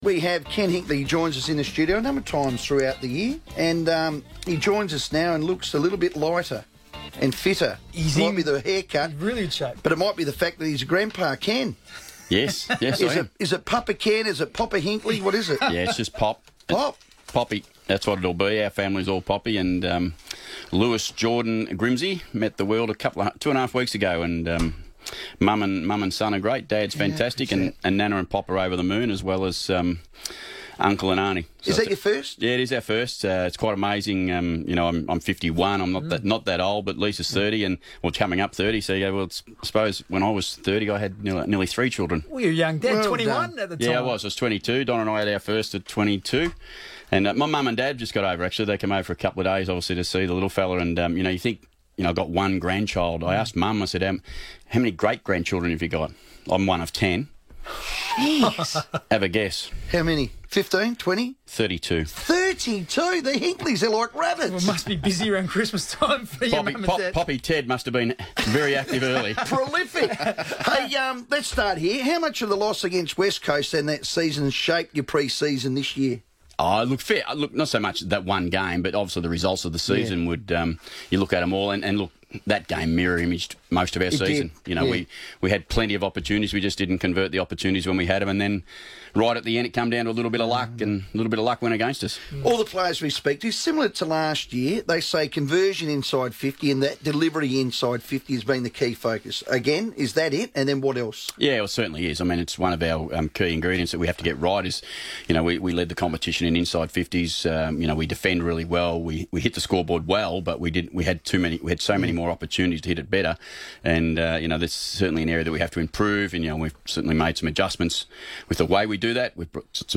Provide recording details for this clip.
live in the studio to discuss the 2018 season.